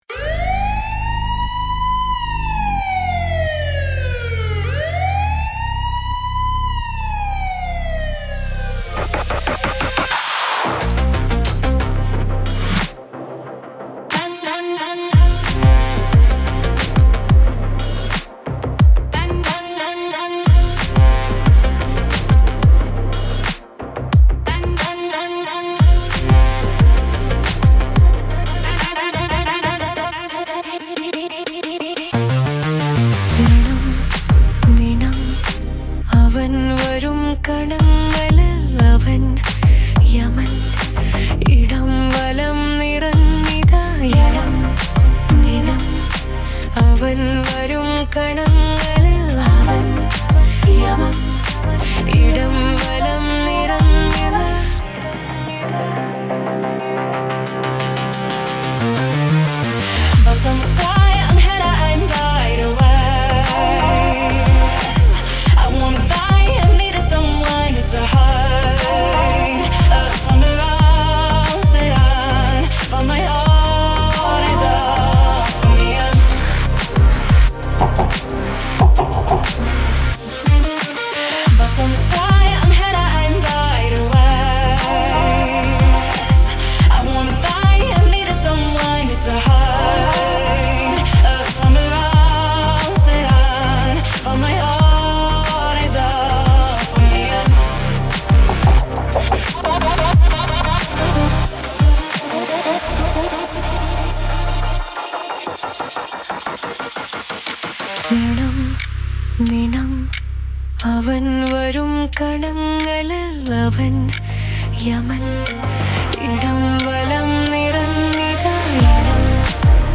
in Electronic
The vocals are dummy and are done in Ai. they will be replaced once final lyrics are ready and vocal recording is done.